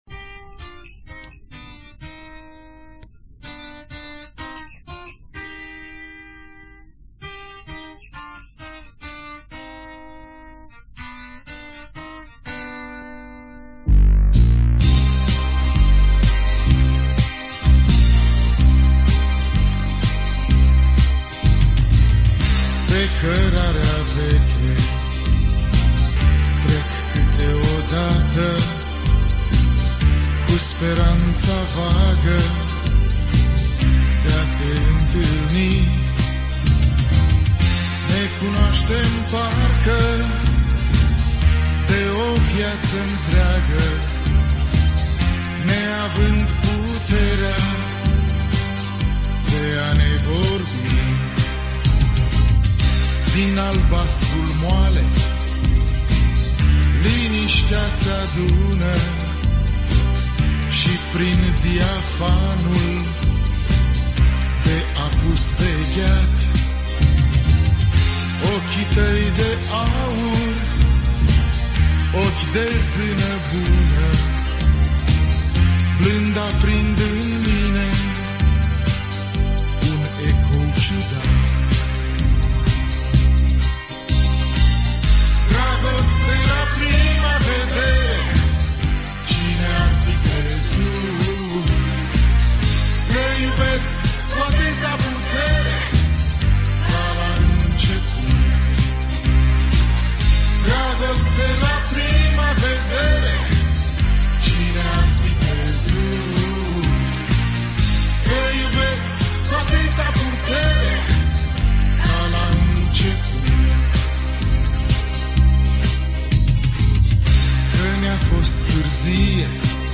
muzică uşoară
dar şi prin interpretarea plină de emoţie.